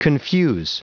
Prononciation du mot confuse en anglais (fichier audio)
Prononciation du mot : confuse